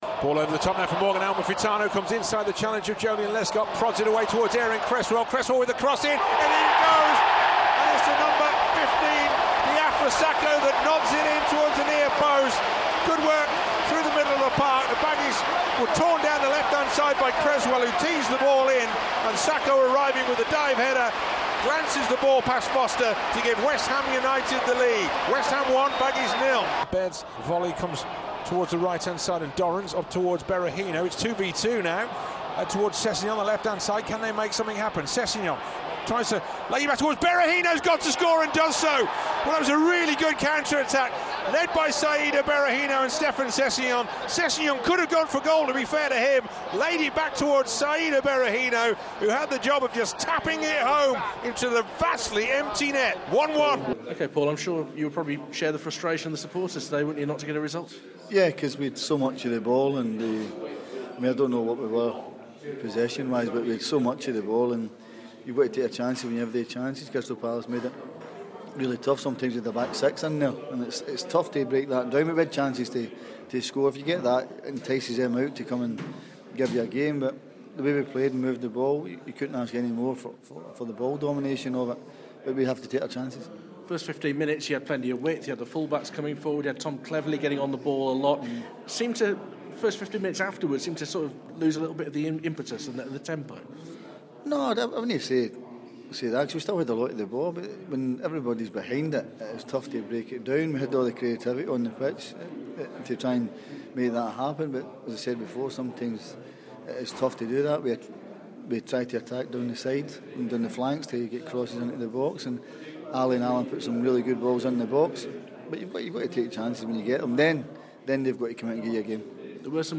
at Upton Park